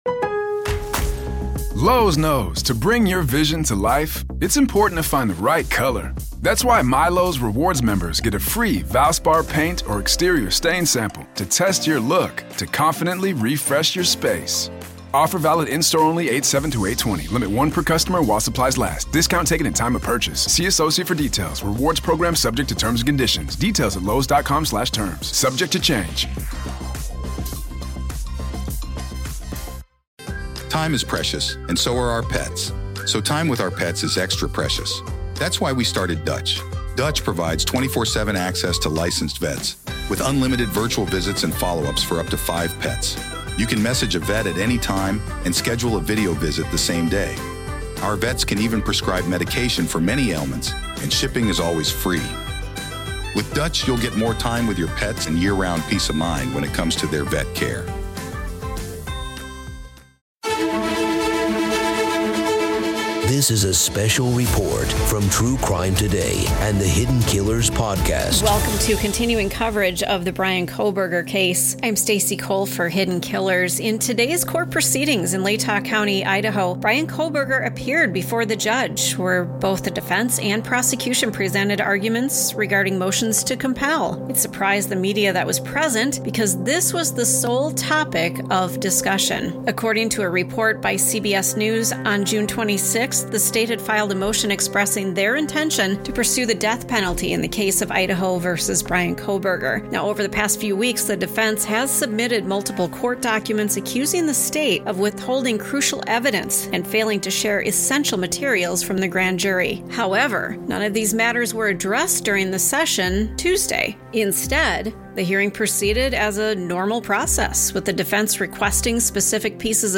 A report on the latest developments of the high-profile case of Idaho v. Bryan Kohberger and his appearance in Latah County Court today.